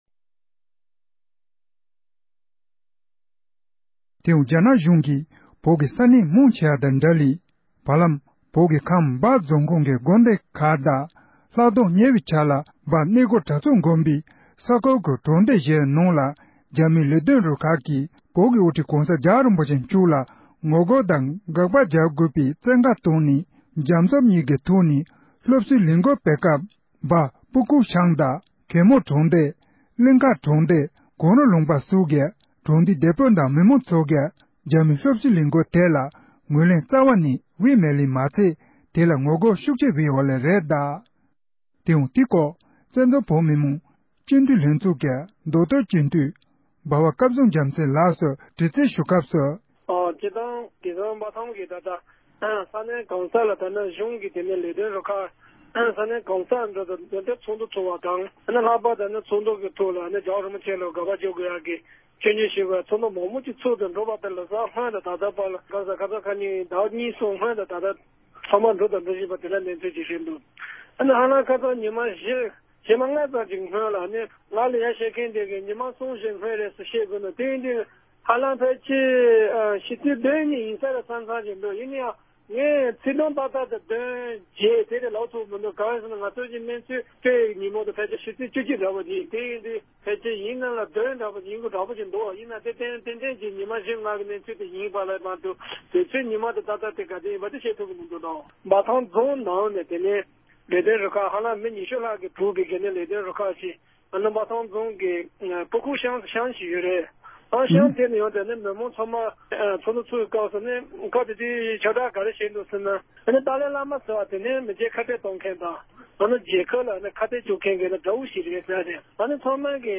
འབྲེལ་ཡོད་མི་སྣར་བཀའ་འདྲི་ཞུས་པ་ཞིག